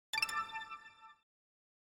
After Effects: Рендеринг завершен успешно (просчет выполнен), звук завершения